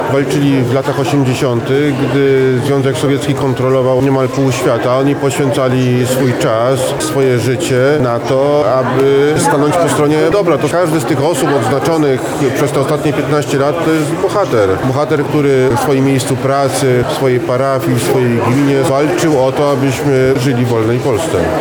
O uhonorowanych osobach mówi dr Mateusz Szpytma, Zastępca Prezesa Instytutu Pamięci Narodowej: